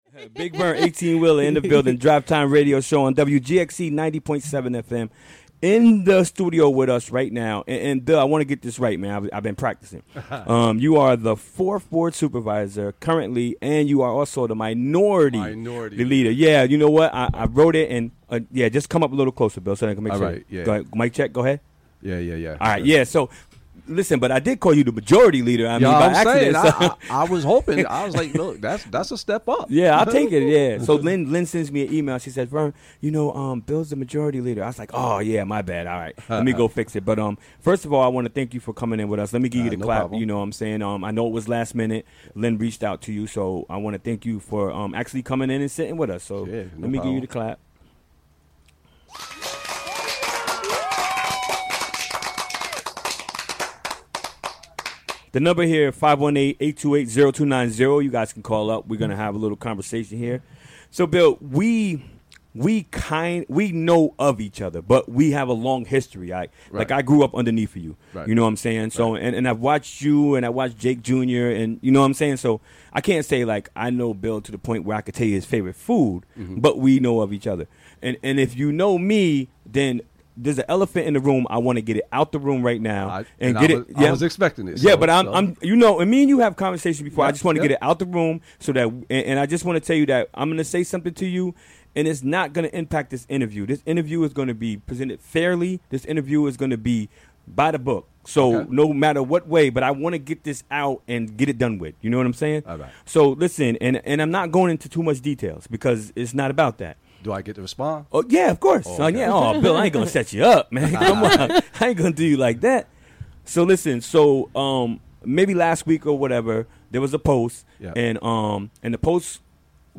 Recorded during the WGXC Afternoon Show on Wednesday, September 27, 2017.